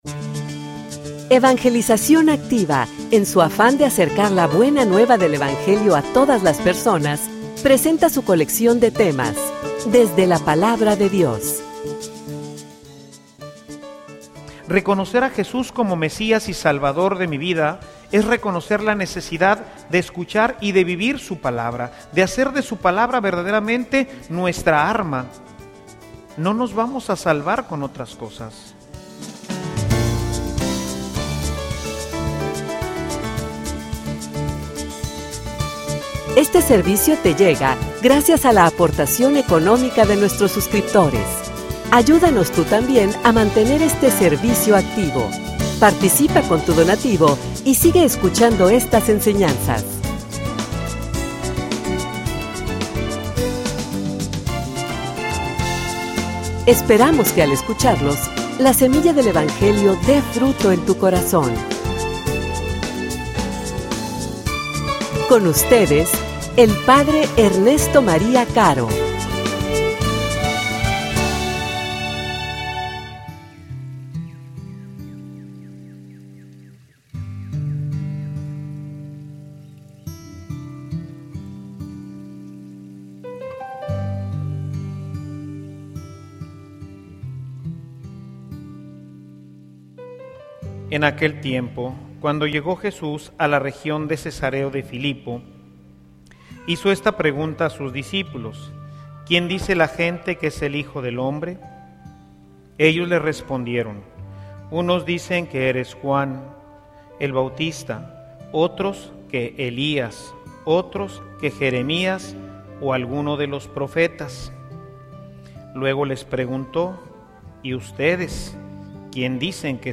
homilia_Tu_eres_el_Mesias_Yo_confio_en_ti.mp3